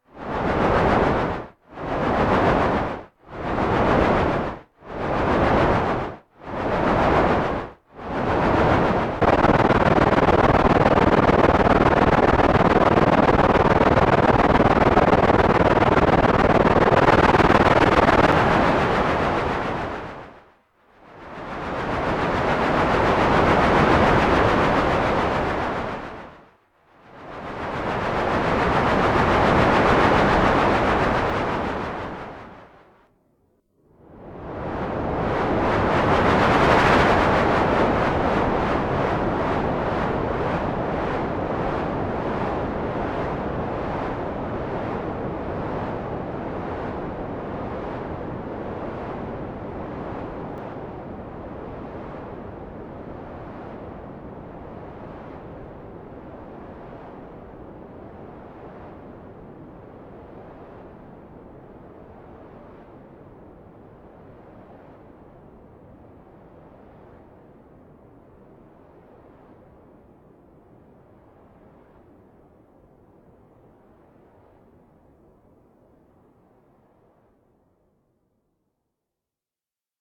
D     ENVELOPE SHAPER
Same source. Setting of pots : Attack = 6, On = 1, Decay = 7, Off = 0
Plus the Trapezoid voltage on the Decay : matrix pin 11 I, to get exponential slopes.
Again, the sound starts in the 'short' position, switches into 'very short' and then the 'long' one.
After the second long one, the Trapezoid is turned up to 8 before the next trigger..